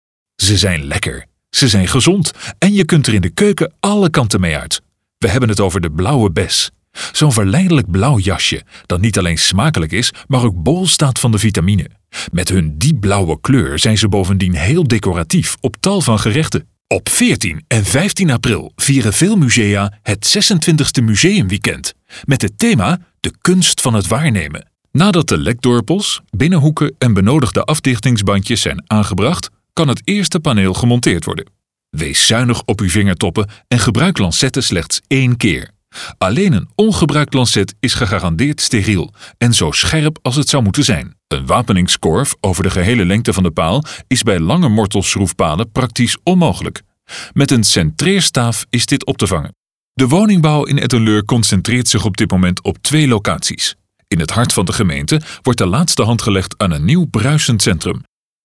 Professioneller holländischer Allround Sprecher.
Kein Dialekt
Sprechprobe: Werbung (Muttersprache):